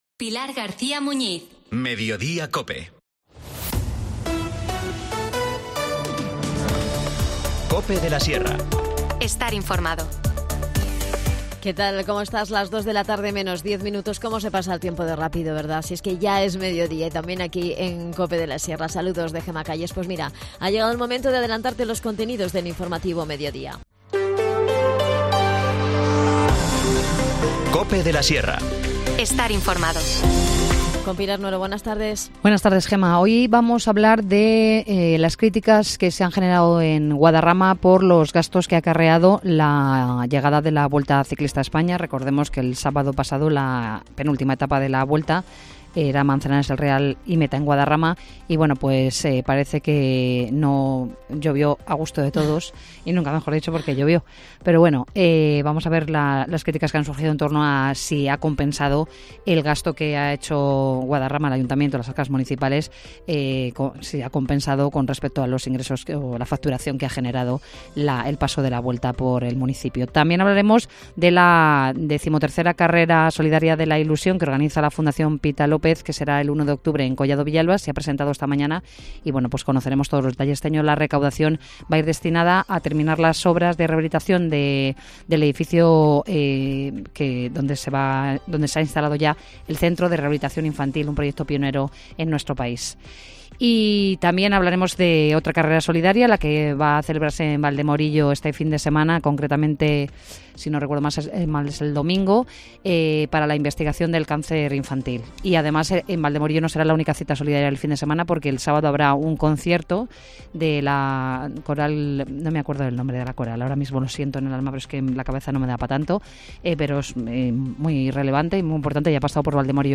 13:50| Magazín| Mediodía COPE de la Sierra, 20 de septiembre de 2023